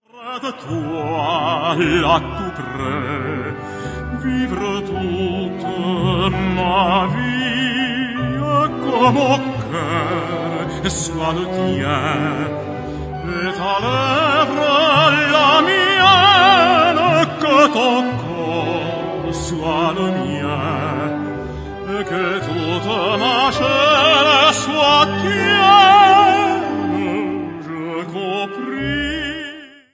key: C-minor